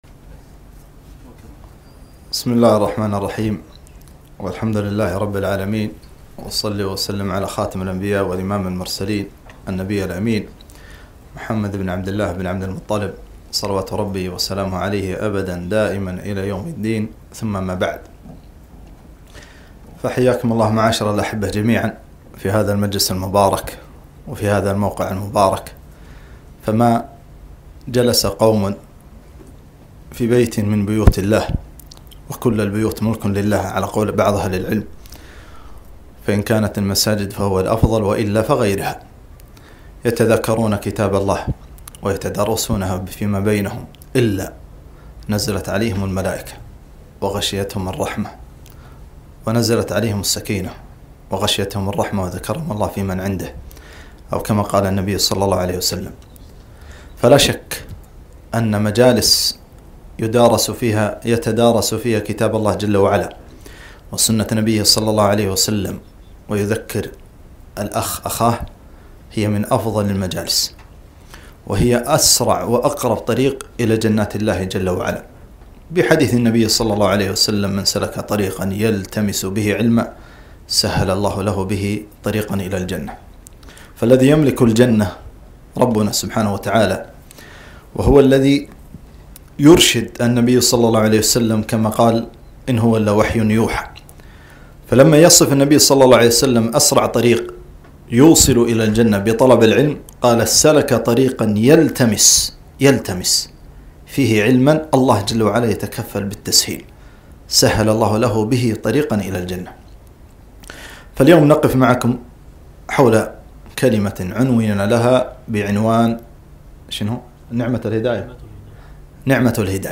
محاضرة - نعمة الهداية